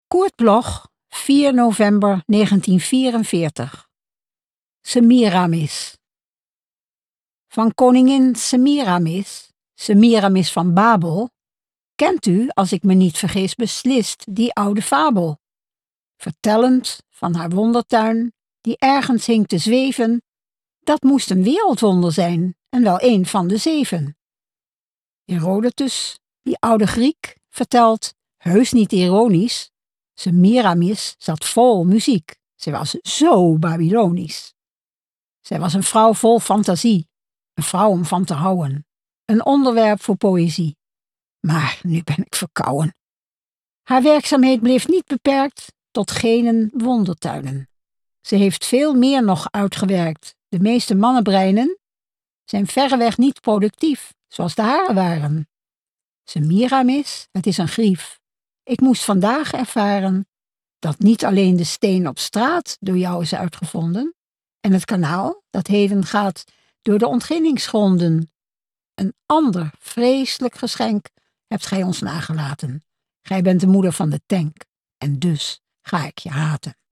Aufnahme: MOST, Amsterdam · Bearbeitung: Kristen & Schmidt, Wiesbaden